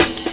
amen pt-1snare2.wav